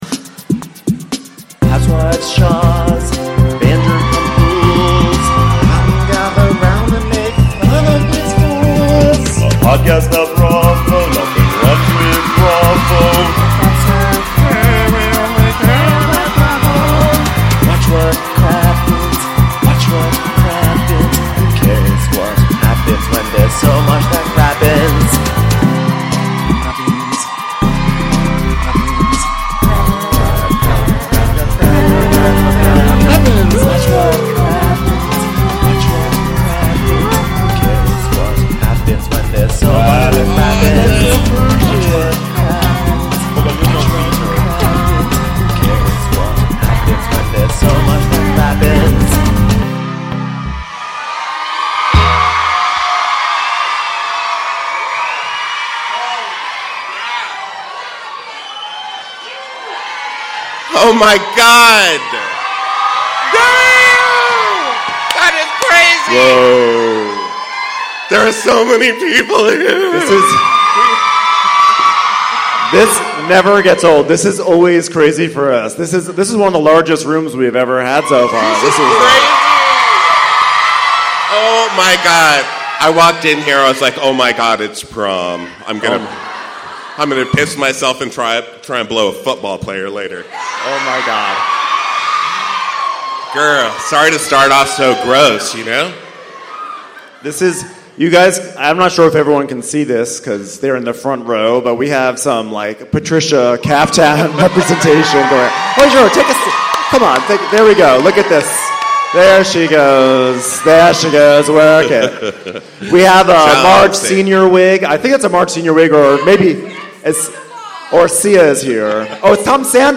#577 RHONJ: Live from SF! Classic Marge
We took our show to San Francisco for a super fun recap of Real Housewives of New Jersey. Come join the crowd as we recap the pasta tasting from hell. And afterwards, stay tuned for the first ever Watch What Crappens wedding proposal!!!